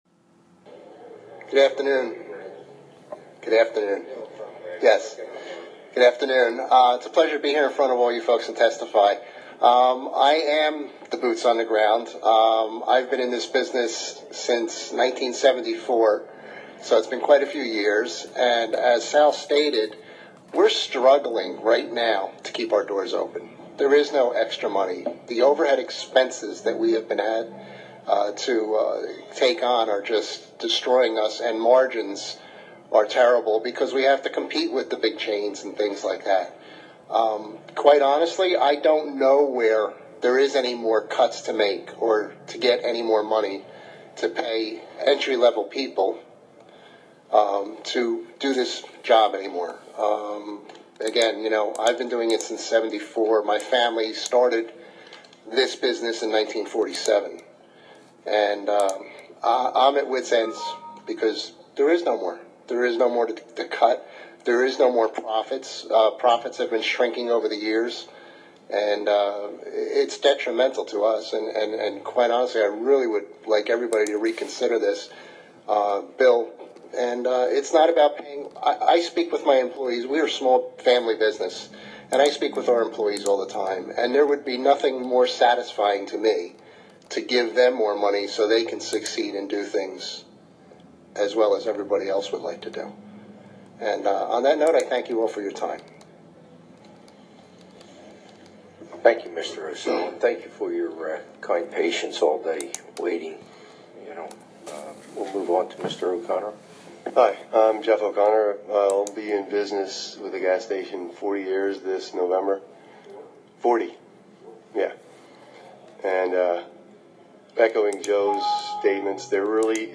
BUSY WEEK IN TRENTON: TWO $15/HR MINIMUM WAGE HEARINGS